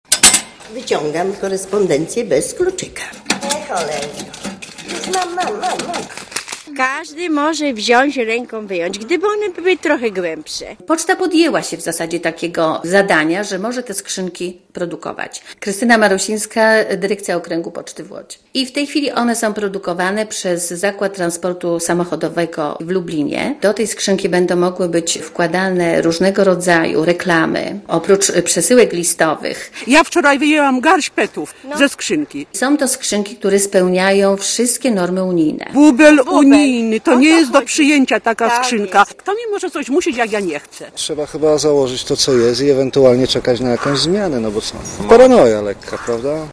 * Posłuchaj co ludzie mówia o nowych skrzynkach*